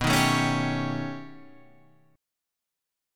B Augmented 9th